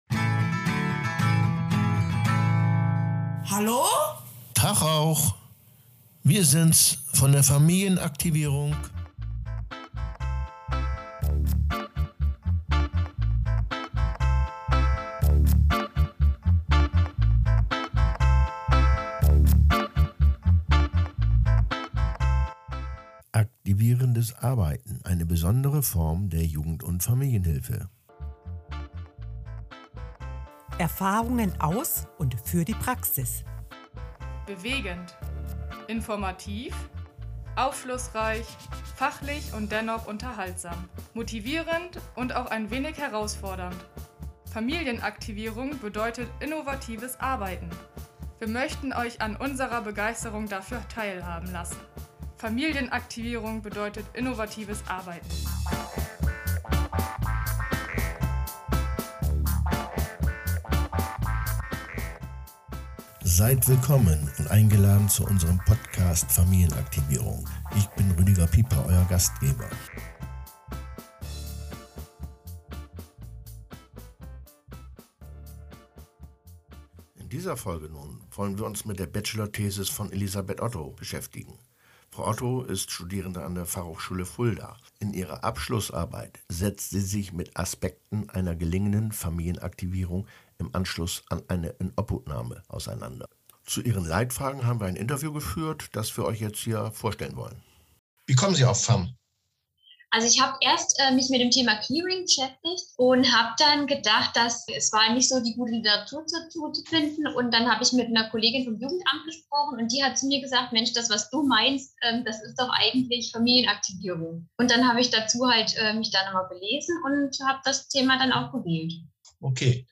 Ein Interview in der Vorbereitung zu einer Bachelor-Arbeit führt zu einem Streifzug duch unterschiedlichste Aspekte familienaktivierenden Arbeitens